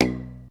2. 02. Percussive FX 01 ZG